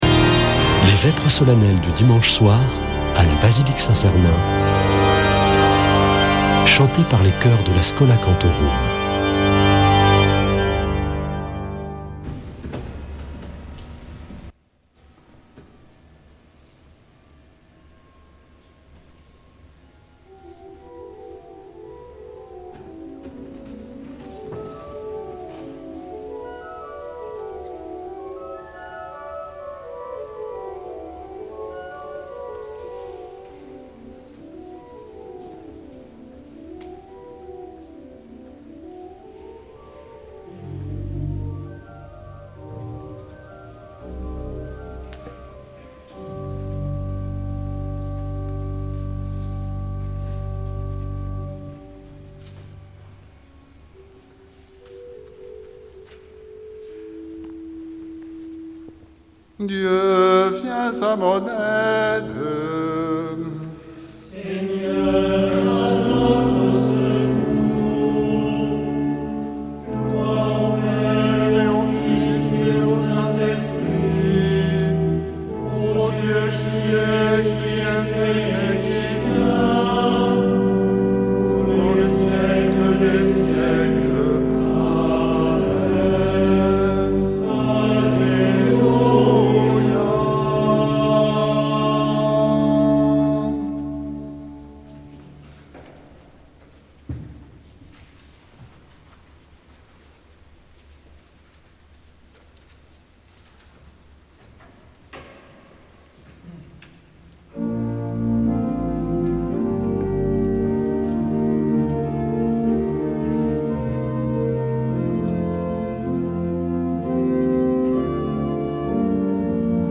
Vêpres de Saint Sernin du 30 nov.
Une émission présentée par Schola Saint Sernin Chanteurs